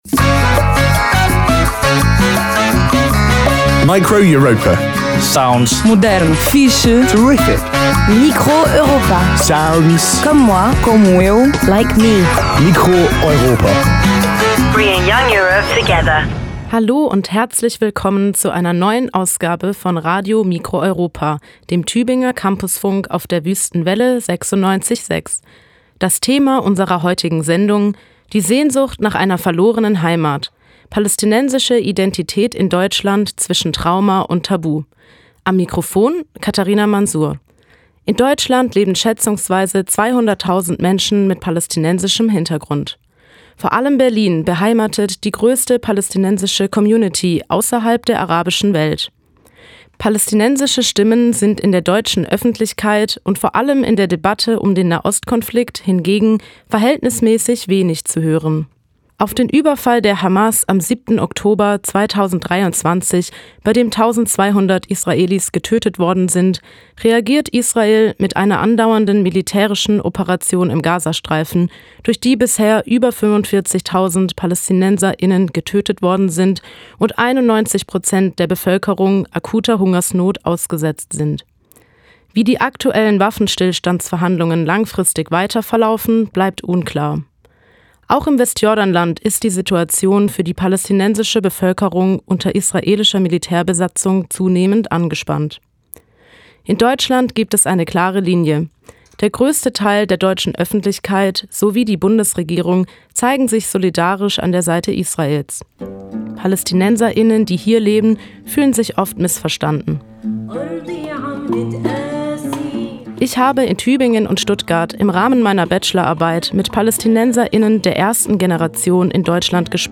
Feature: Die Sehnsucht nach einer verlorenen Heimat. Palästinensische Identität in Deutschland zwischen Trauma und Tabu
Form: Live-Aufzeichnung, geschnitten